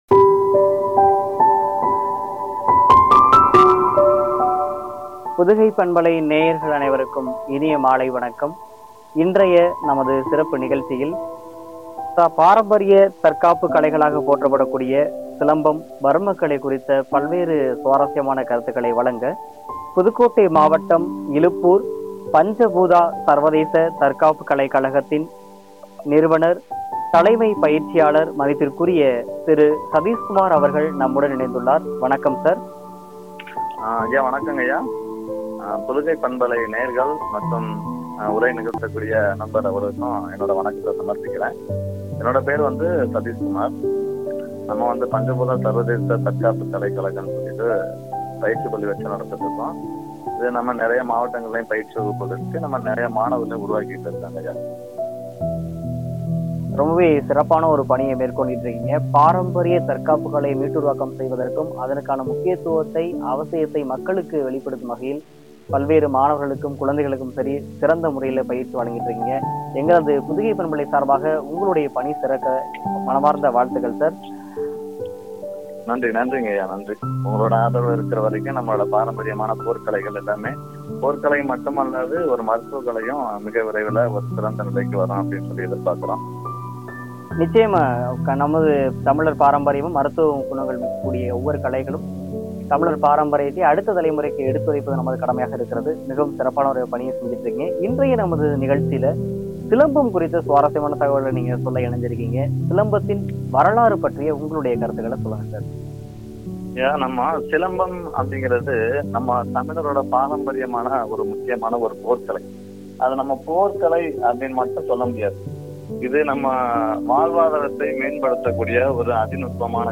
உரையாடல்.